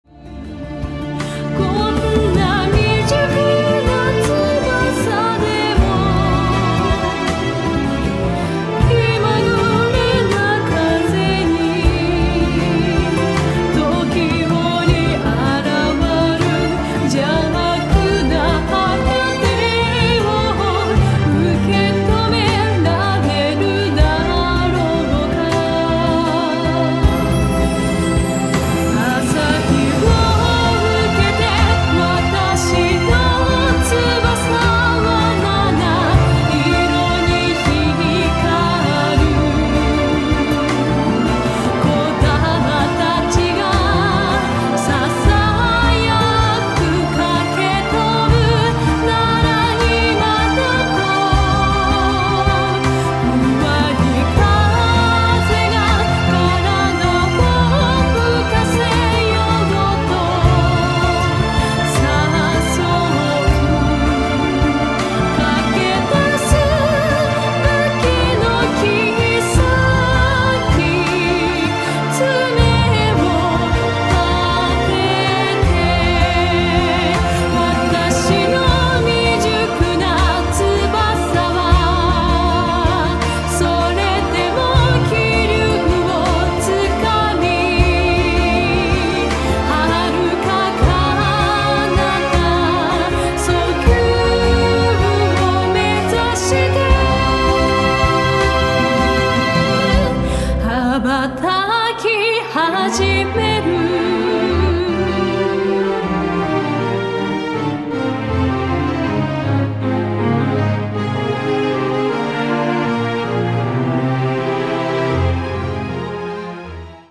オーケストラと渡り合うミュージカルやオペラを思わせる力強い歌声を披露されています。